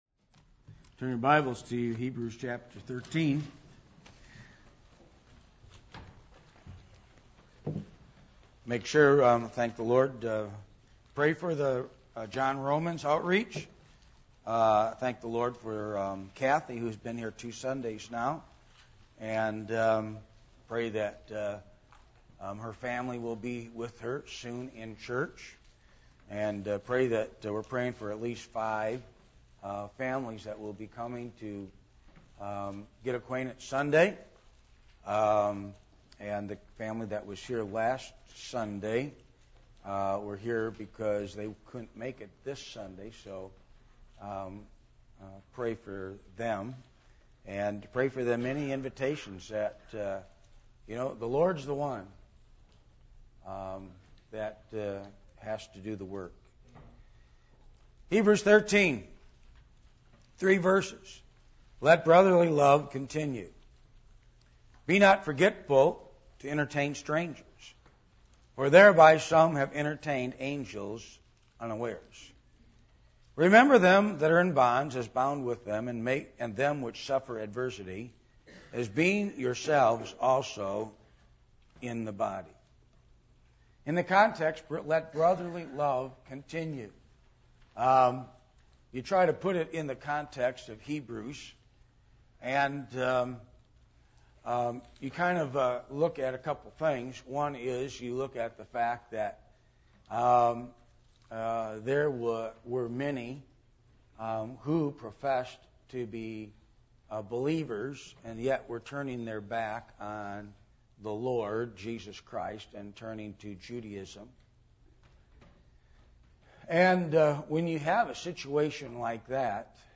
Passage: Hebrews 13:1-3 Service Type: Midweek Meeting %todo_render% « The Promise Of Purification The Attributes Of God